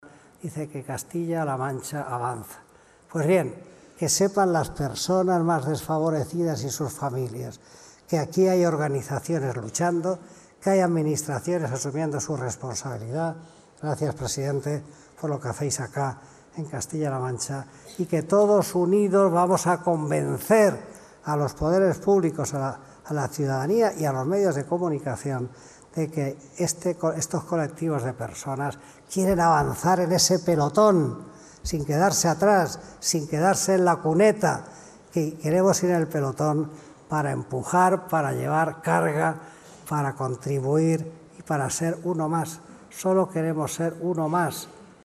Acto de entrega Medalla de Oro Castilla - La Mancha